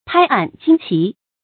拍案惊奇 pāi àn jīng qí
拍案惊奇发音